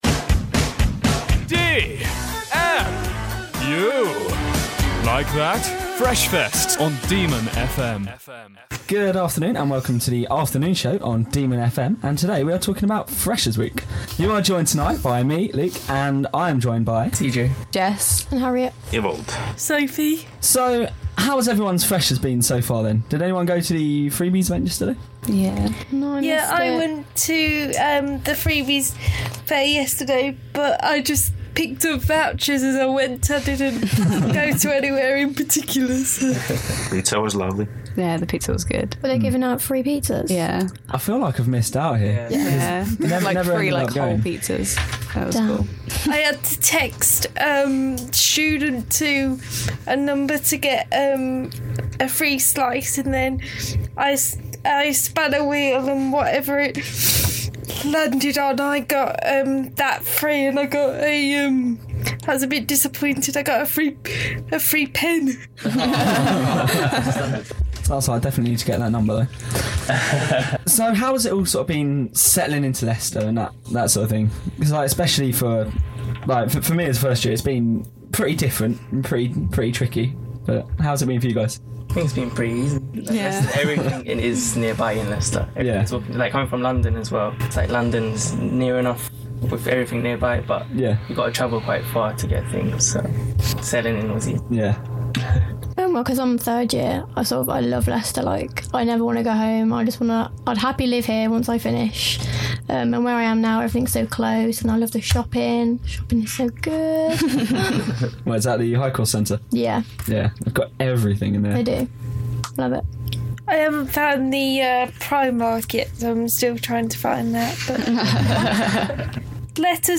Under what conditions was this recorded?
As part of Demon Media’s Open Day, DemonFM gave freshers and students at De Montfort University the chance to make their own mock radio shows to see what joining DemonFM could be like. They were told about what DemonFM is and given relatively little training but managed to pull off some epic shows.